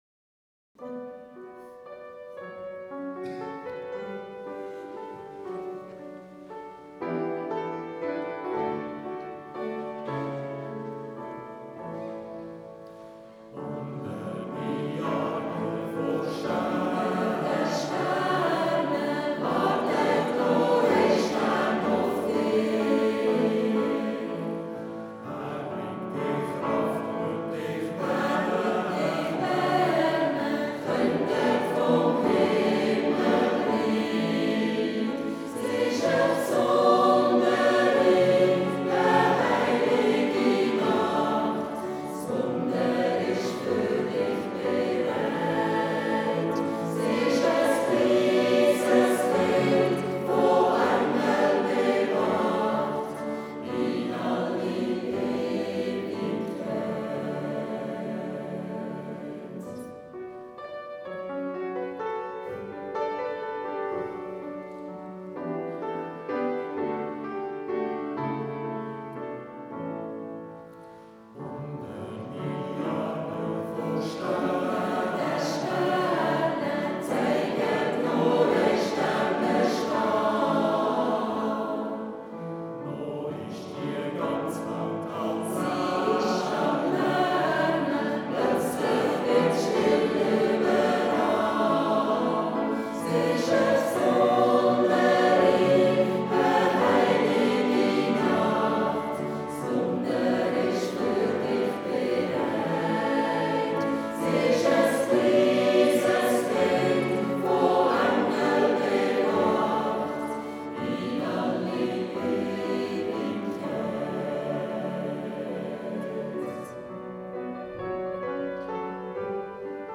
Buttisholz LU